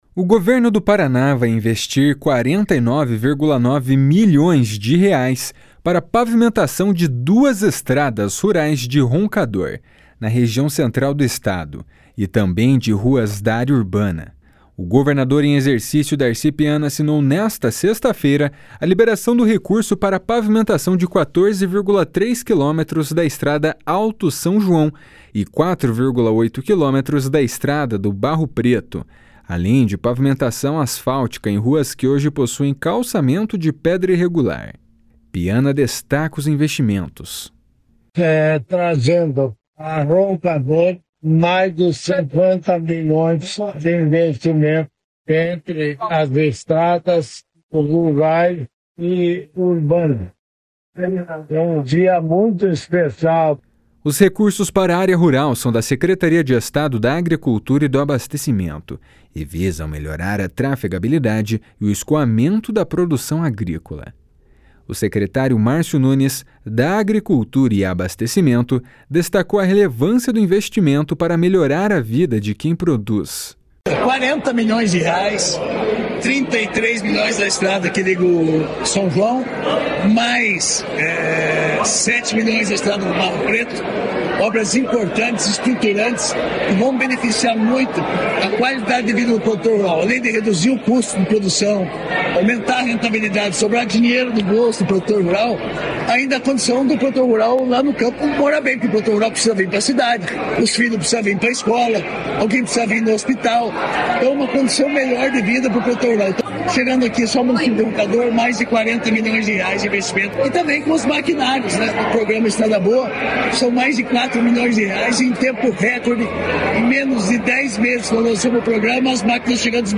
Piana, destaca os investimentos. // SONORA DARCI PIANA //
// SONORA MÁRCIO NUNES //